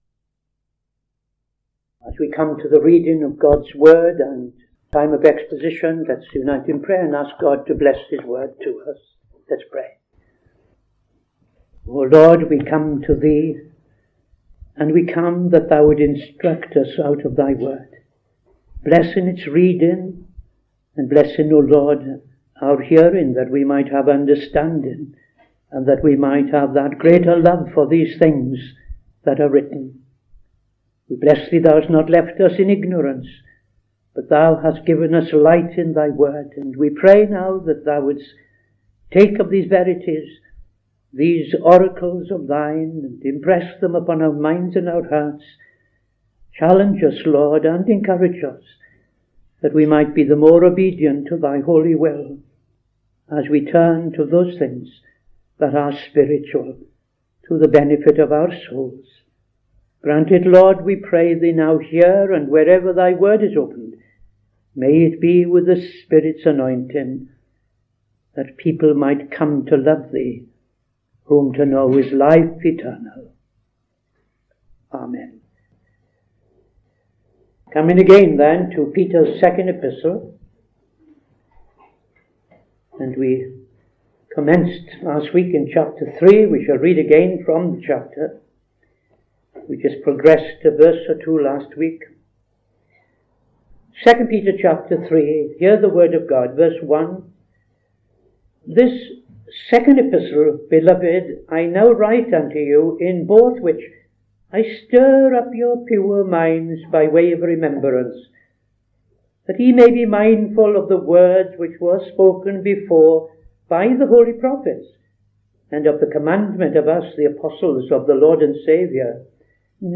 Saturday Sermon - TFCChurch
Opening Prayer and Reading II Peter 3:1-12